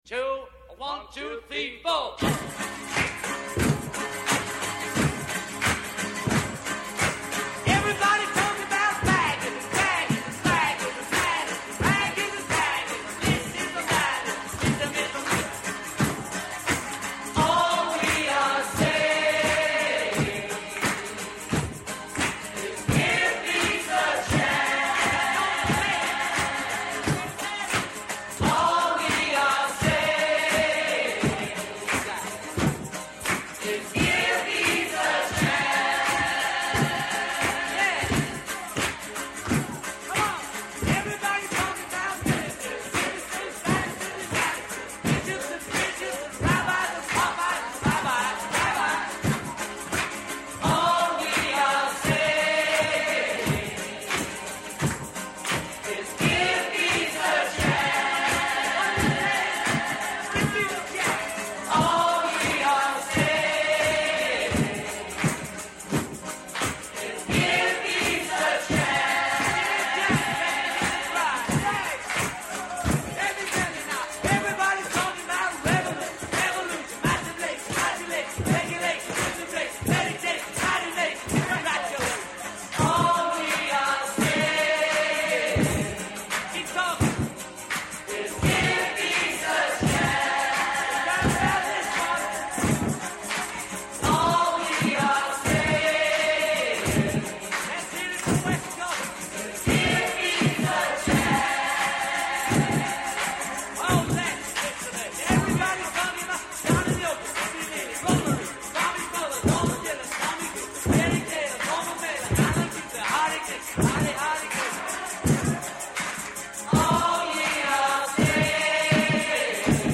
Στην σημερινή εκπομπή καλεσμένοι :